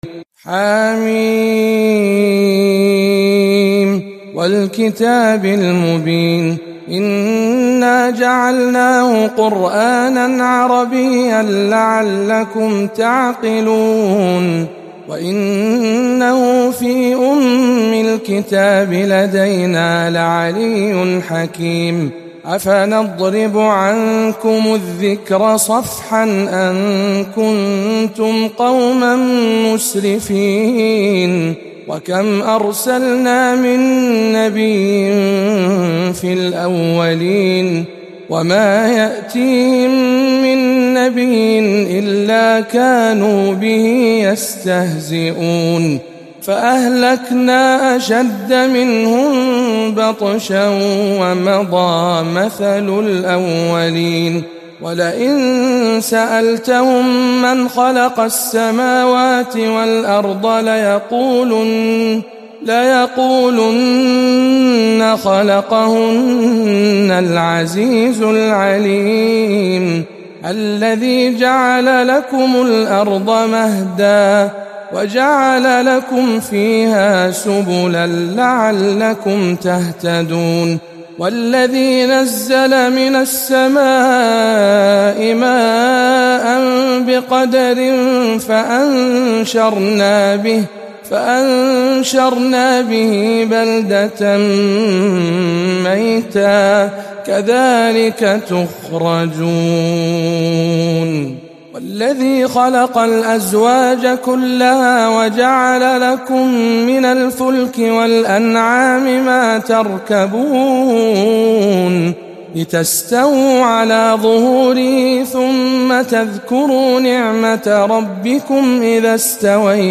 سورة الزخرف بجامع عمر بن الخطاب بمكة المكرمة - رمضان 1439 هـ